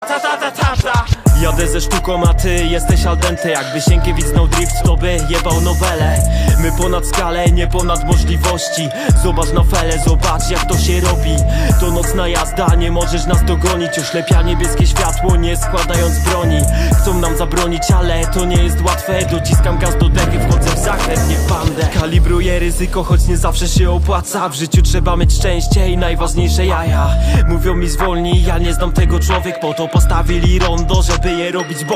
Kategorie Rap